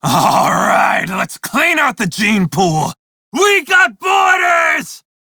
DRJCrewEnemySpot2430RJRVMale_en.ogg